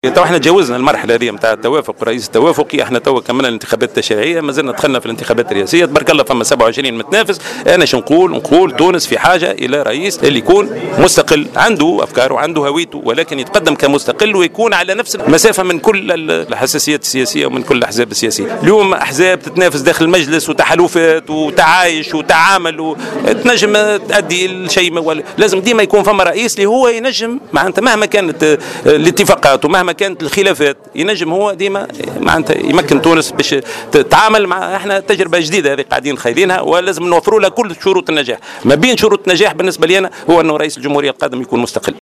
أكد المترشح المستقل للانتخابات التشريعية منذر زنايدي على هامش افتتاح حملته الإنتخابية بصفاقس اليوم الأحد 2 نوفمبر 2014 أن تونس بحاجة لرئيس مستقل يكون على نفس المسافة من جميع الأحزاب السياسية.